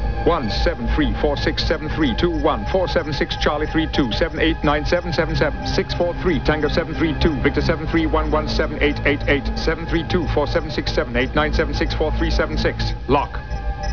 Picard listing a bunch of numbers to lock the computer (151KB) - wav